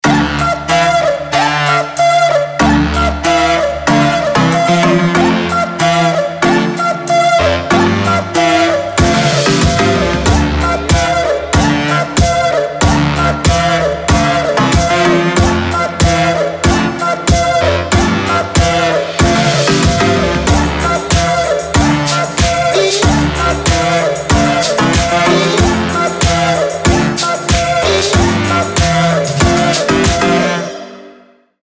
Electronic
Очень забавный мотив